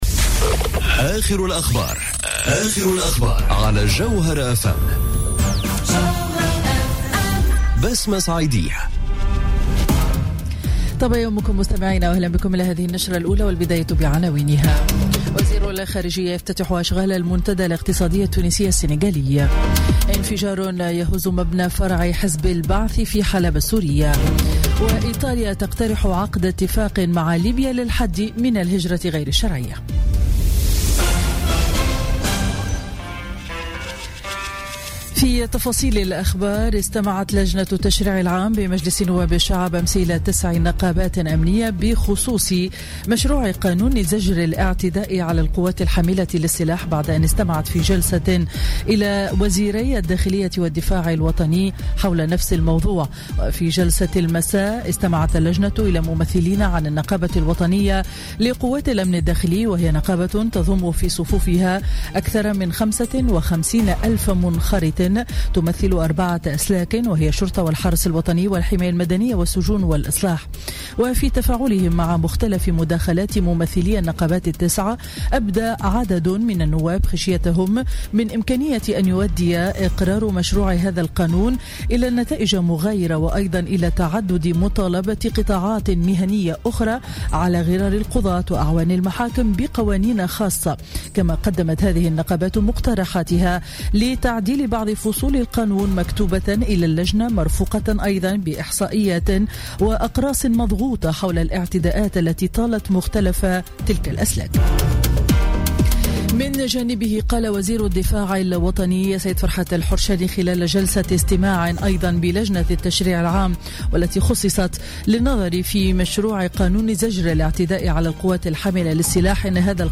نشرة أخبار السابعة صباحا ليوم الجمعة 14 جويلية 2017